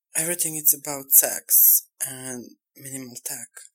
描述：一切都是关于性和最小的技术
Tag: 150 bpm Dirty Loops Vocal Loops 645.32 KB wav Key : Unknown